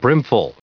Prononciation du mot brimful en anglais (fichier audio)
Prononciation du mot : brimful